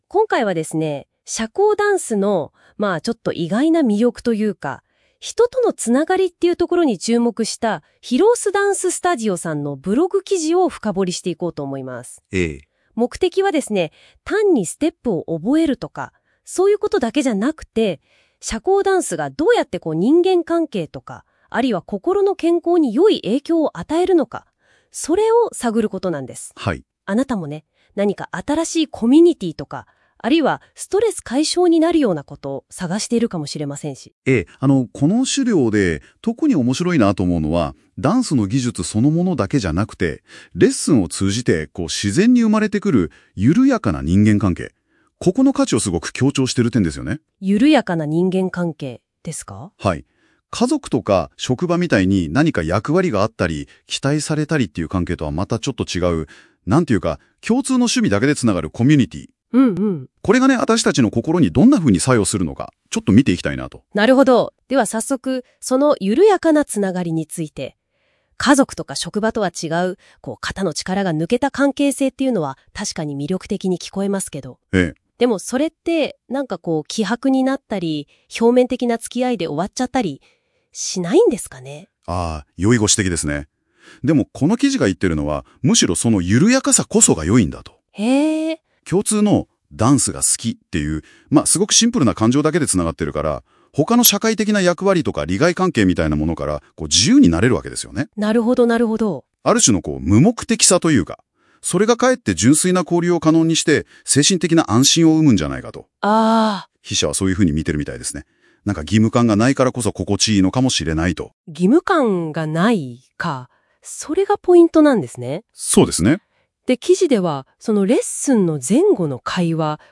このブログについて、AIで生成した会話音声（約5分）も、お楽しみいただけます。